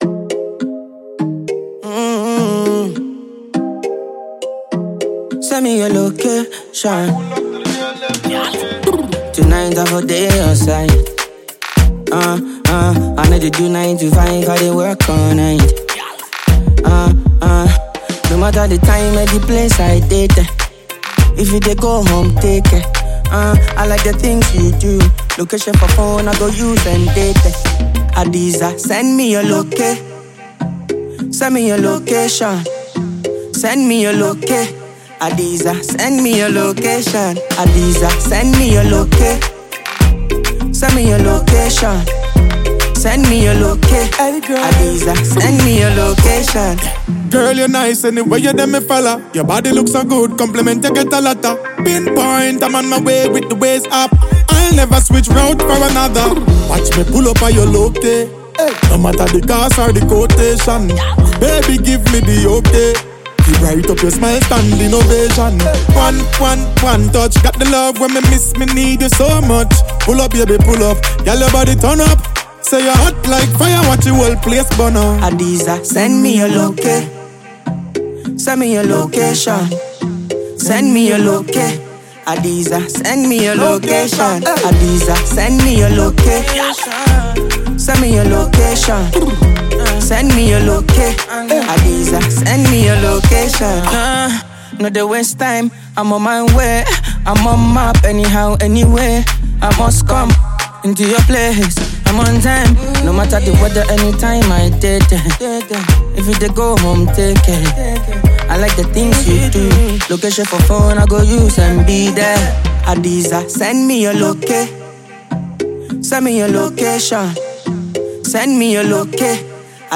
Ghanaian Afro-Pop & Dancehall musician